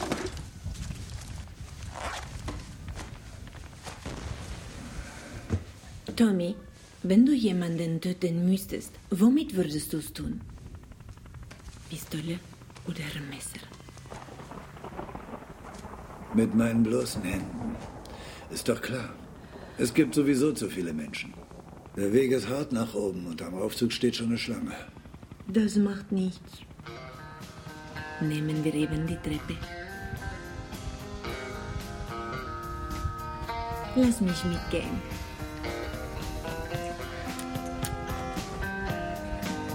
spanische und deutsche Profi Sprecherin. Sehr variable Stimme, auch Zeichentrick, und Kinderstimmen
Sprechprobe: Werbung (Muttersprache):
german / spanish female voice over artist